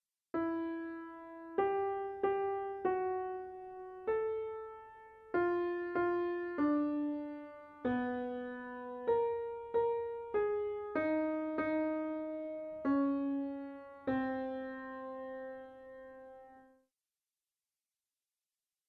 This fugue isn't really built on Azmon, but rather on a 12-tone row that borrows Azmon's rhythm and general melodic shape, while avoiding the repetition of pitches.
theme*of my unfinished string quartet, which I wrote about here. (Creepy synth recording here.)